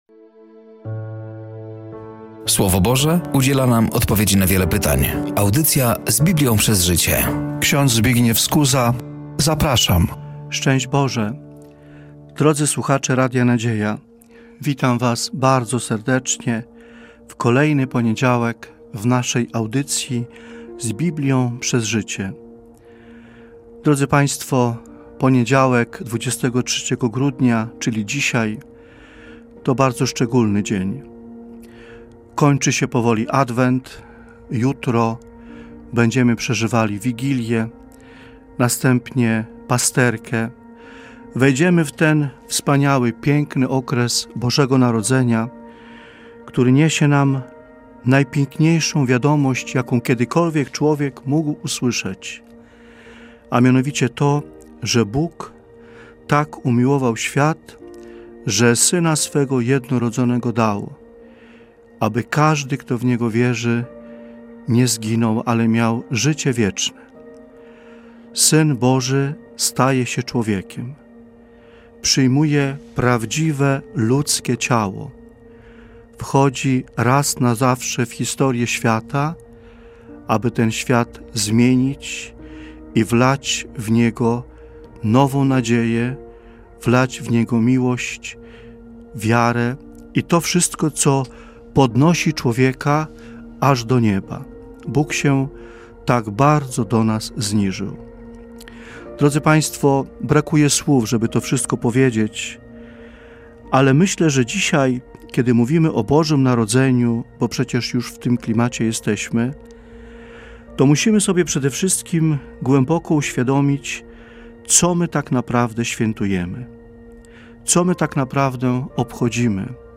Audycja ,,Z Biblią Przez Życie” emitowana jest w poniedziałki o godzinie 15.15.